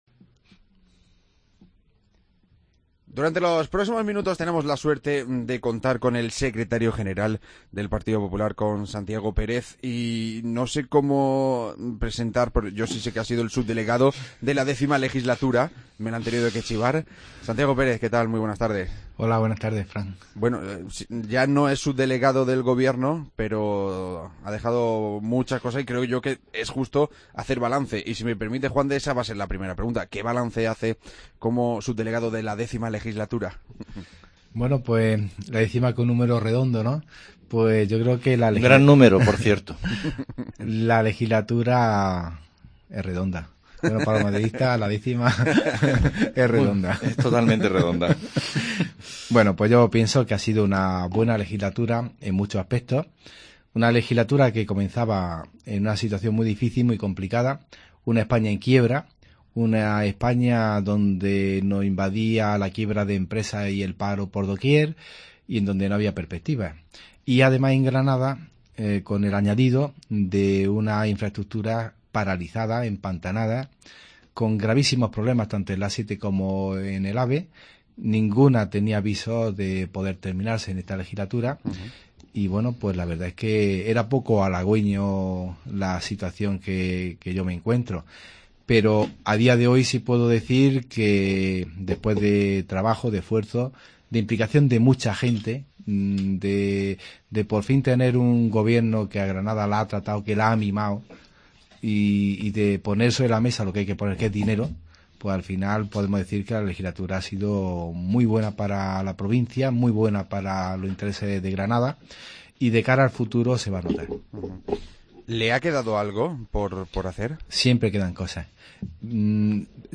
Entrevista a Santiago Pérez, candidato del PP